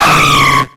Cri de Cadoizo dans Pokémon X et Y.